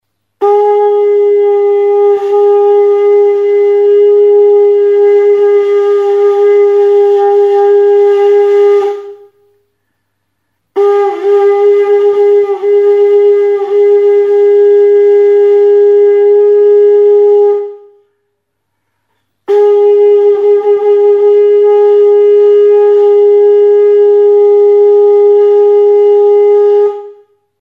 CARACOLA | Soinuenea Herri Musikaren Txokoa
Recorded with this music instrument.
Music instruments: CARACOLA Classification: Aerophones -> Lip vibration (trumpet) -> Natural (with / without holes) Location: Erakusketa; aerofonoak Explanation of acquisition: Erosia; 1971ko irailean Galiziako kostaldeko Grove herrian erosia. Description: Punta zulaturik tronpeta gisako ahokadura duen itsas kurkuilua.